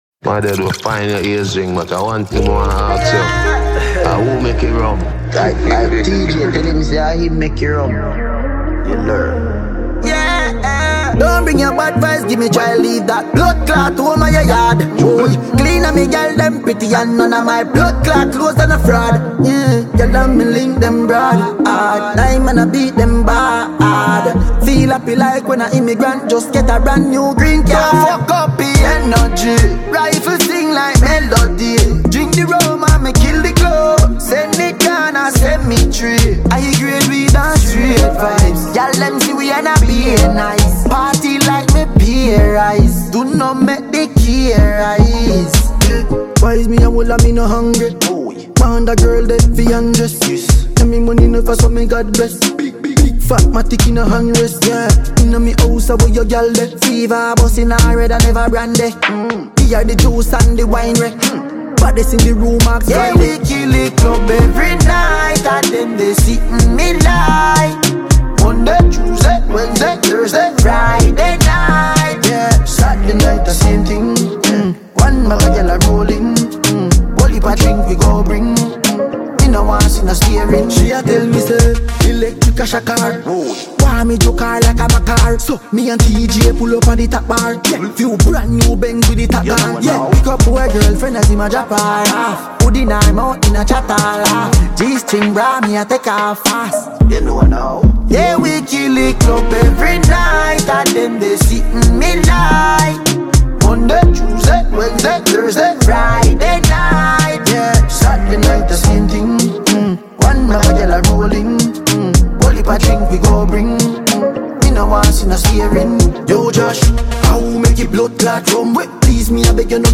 Talented songwriter and dancehall Jamaican musician